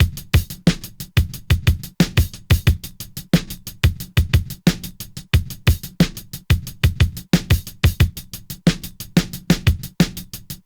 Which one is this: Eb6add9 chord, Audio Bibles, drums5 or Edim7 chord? drums5